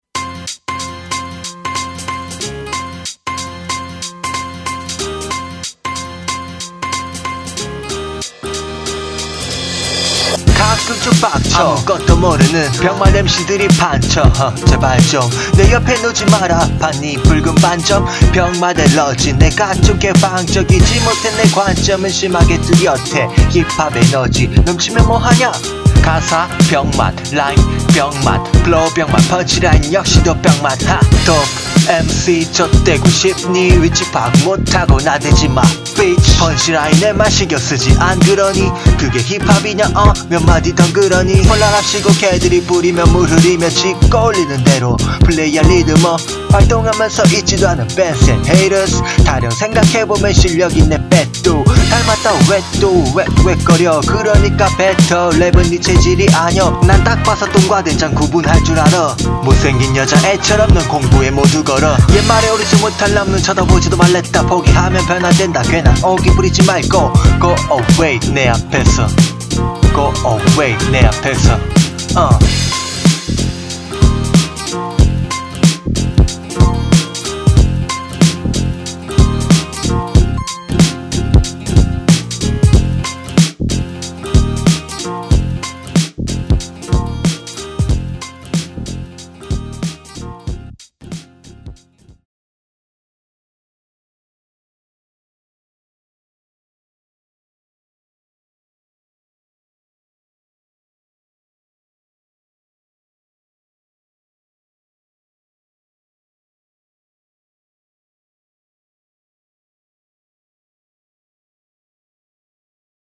중간고사때문에 분노게이지상승랩핑
그냥 평소에맘에안든것diss